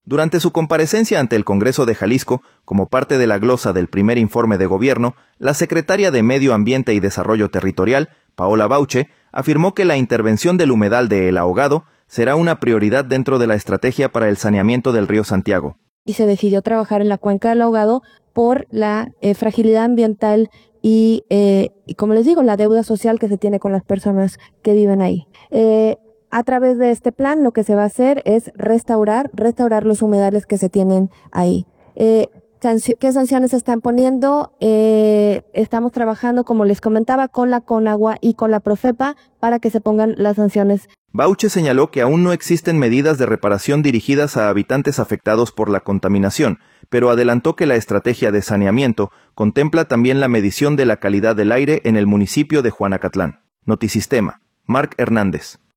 Durante su comparecencia ante el Congreso de Jalisco, como parte de la glosa del primer informe de gobierno, la secretaria de Medio Ambiente y Desarrollo Territorial, Paola Bauche, afirmó que la intervención del humedal de El Ahogado será una prioridad dentro de la estrategia para el saneamiento del Río Santiago.